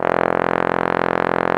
Index of /90_sSampleCDs/AKAI S-Series CD-ROM Sound Library VOL-2/1095 TROMBON